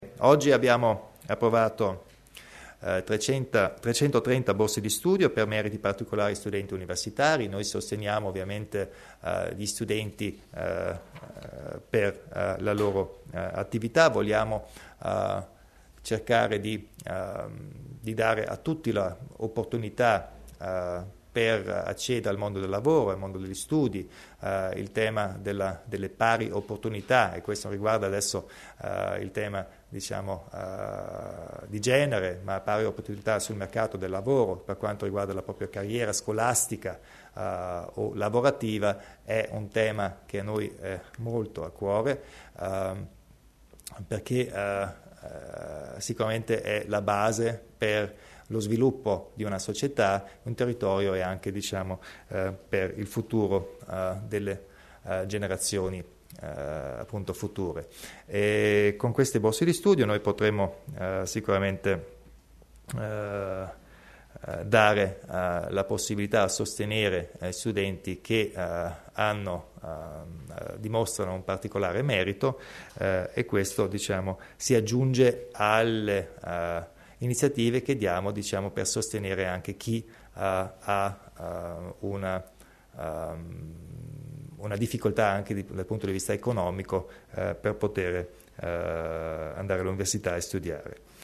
Il Presidente Kompastcher spiega i nuovi contributi per le borse di studio